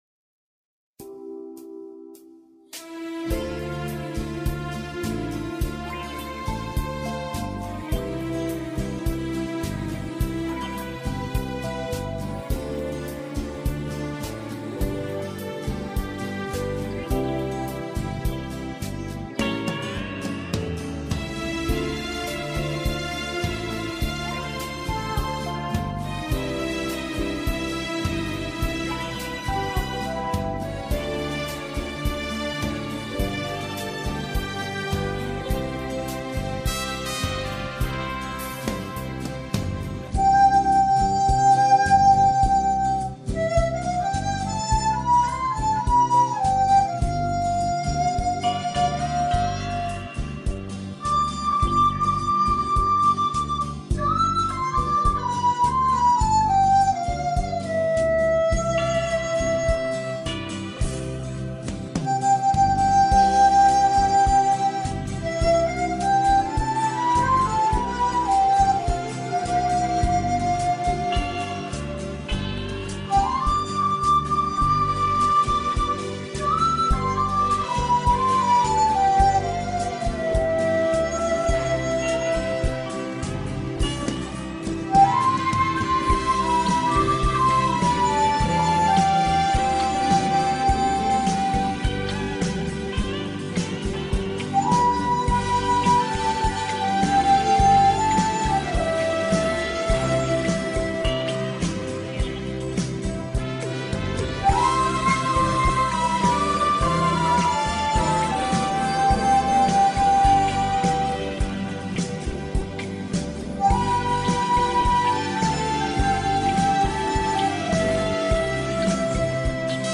（笛子新版）
笛声悠扬，很生动！